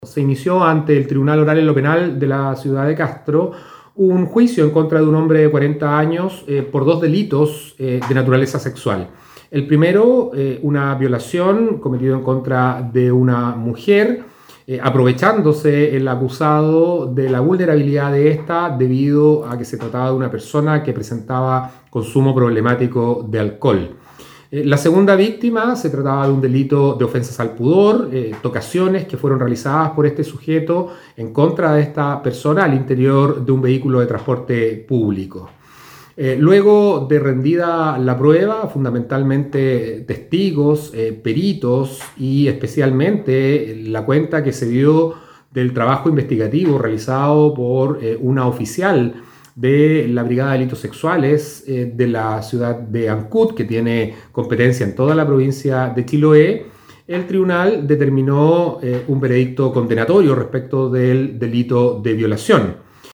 Sobre la resolución del Tribunal Oral en lo Penal de Castro, se refirió el fiscal jefe del ministerio Público, Enrique Canales.